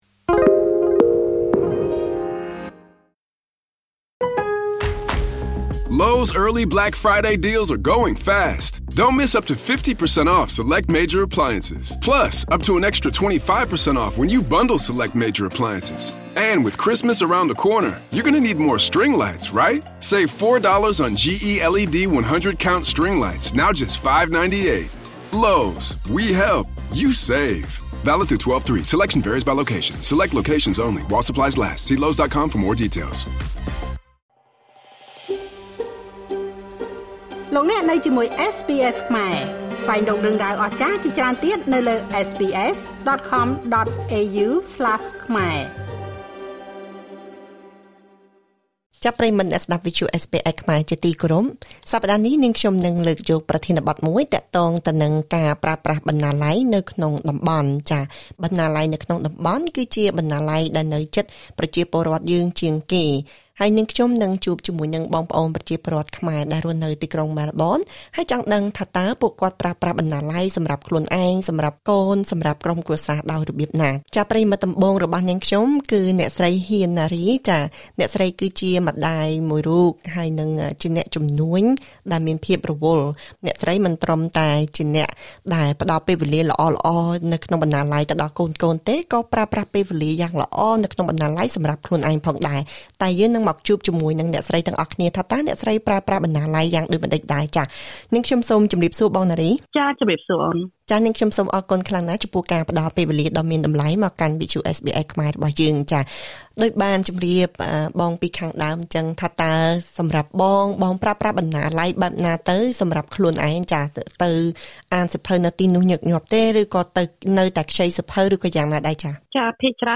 សូមស្តាប់បទសម្ភាសន៍ជាមួយប្រជាពលរដ្ឋខ្មែរអូស្រ្តាលីដែលរស់នៅទីក្រុងមែលប៊ន។